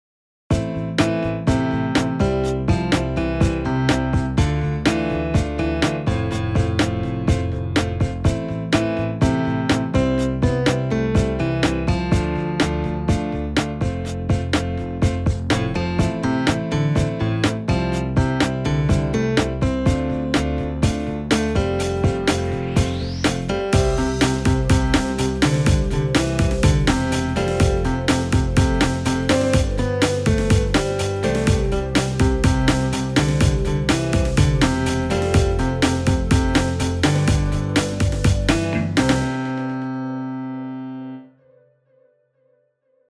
in C guide for bass and baritone